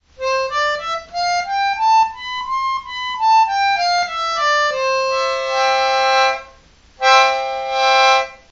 Triola
listen) is a children's musical instrument, a hand-tuned single-tone wind harmonica.
It works by blowing into the instrument and pressing the coloured keys.
Triola.ogg